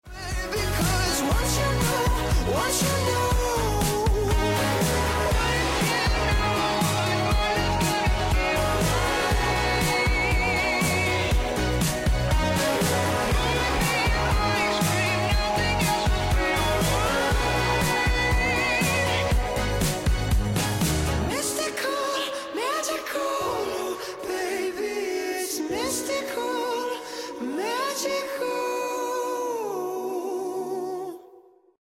whimsical